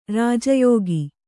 ♪ rāja yōgi